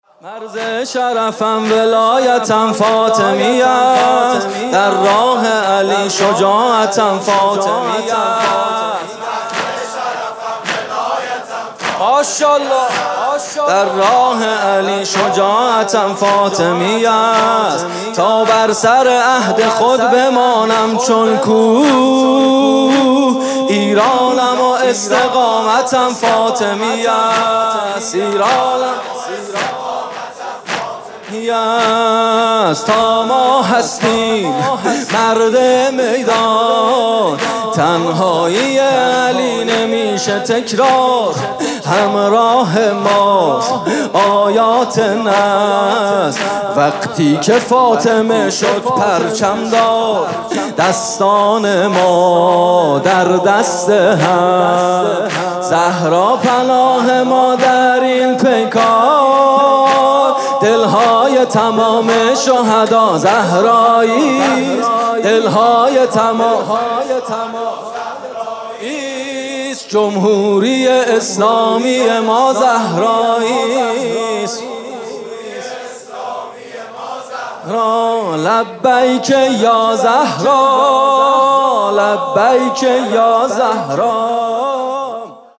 music-icon رجز